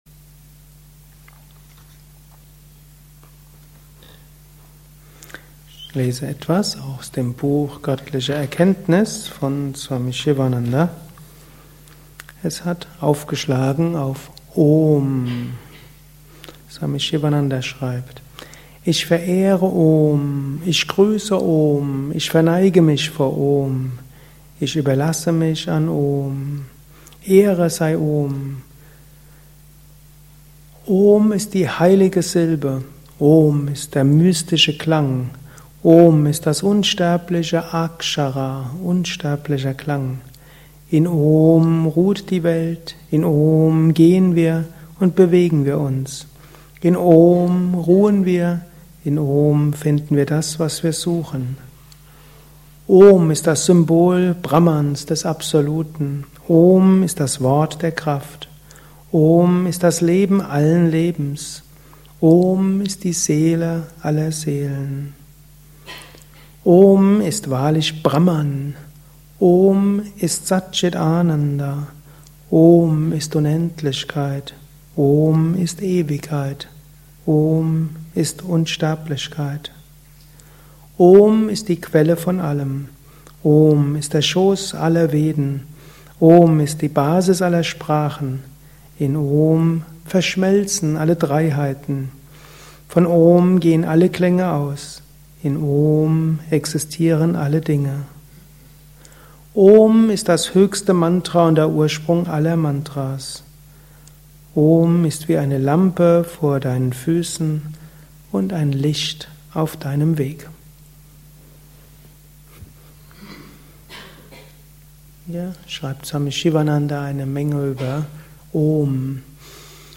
Lausche einem Vortrag über Mantras. Lausche einem Vortrag über OM